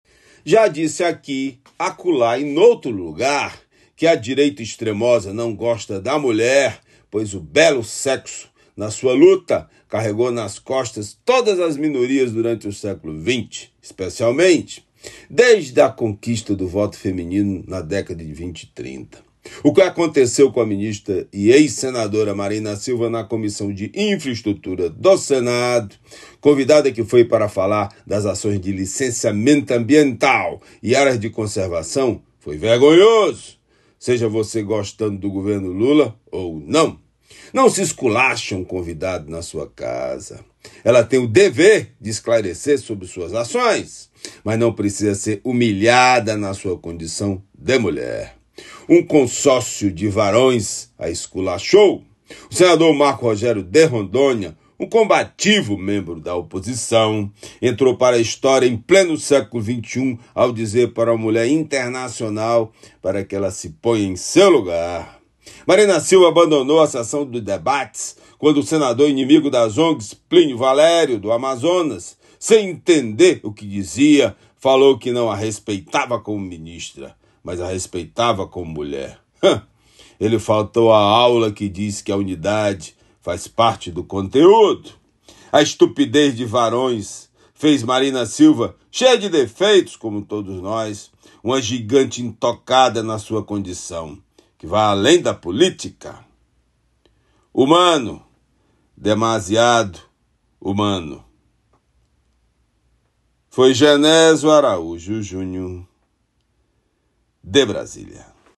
comentario-28-05.mp3